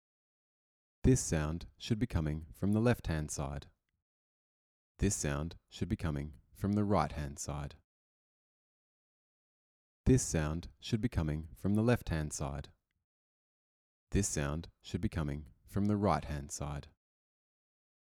A test clip to test left and right speakers.
audio-stereo-test.mp3